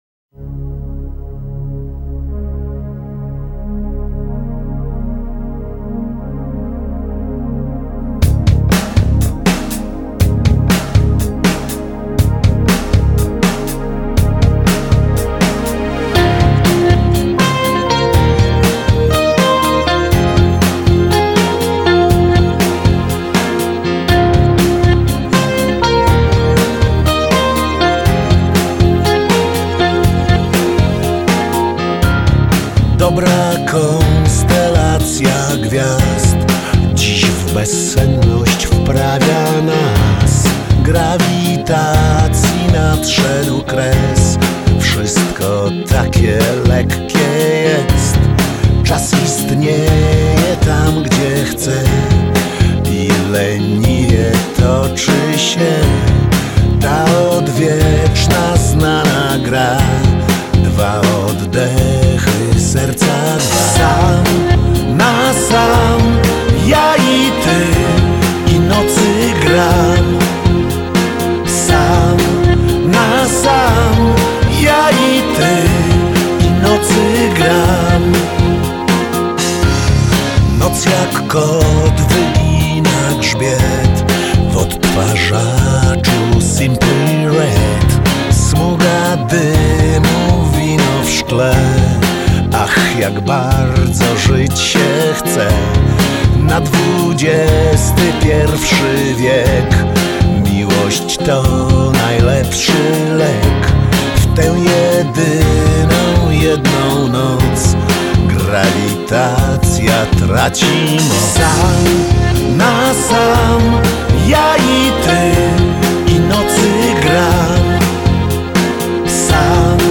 popular Polish rock band